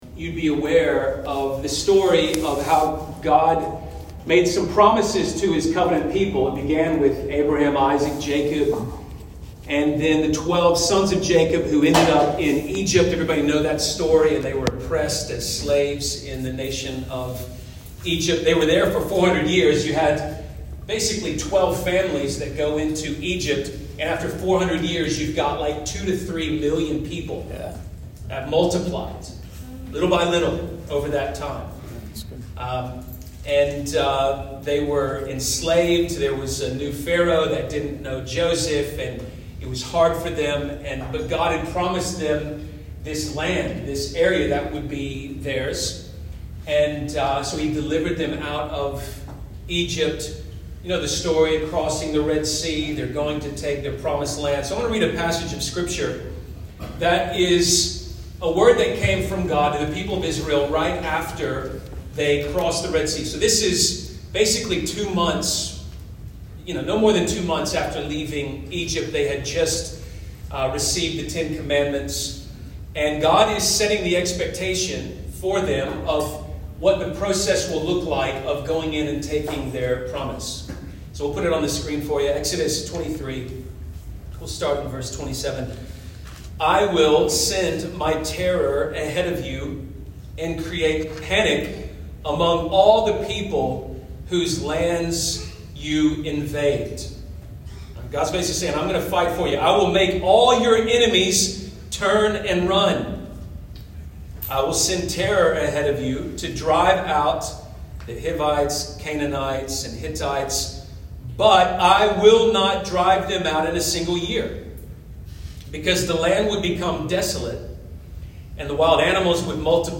(Due to technical difficulties, the audio at the start is muffled, but it does improve at the 17 minute mark.)